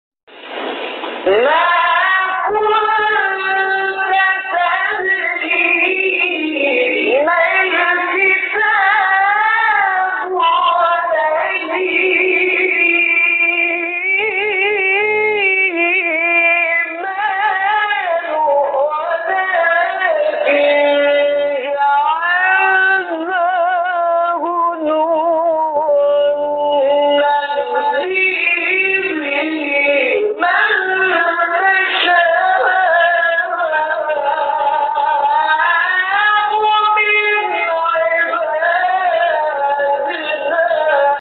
نغمات صوتی از قاریان ممتاز کشور